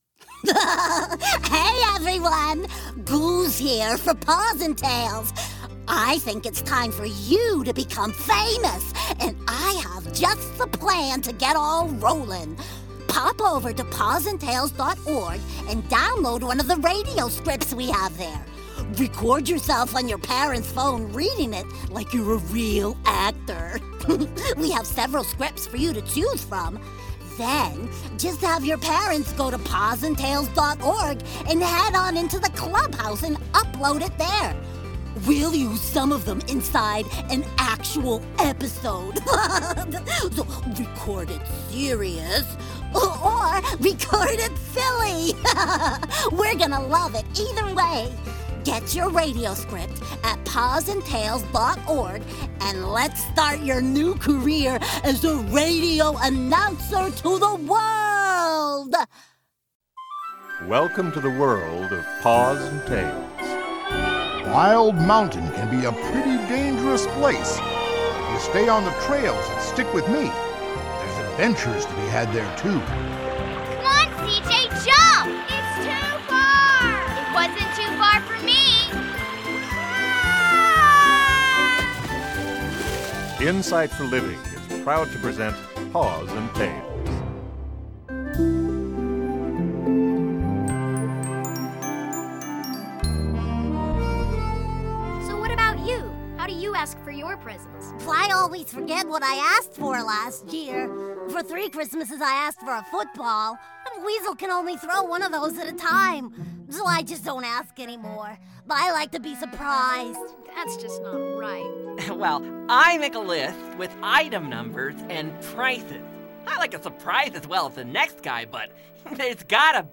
Journey into an exciting world where the truths of God's Word are shared by a cast of lovable animal characters and the lessons learned are entertaining and life-changing.
Find adventure, fun, and music in Wildwood!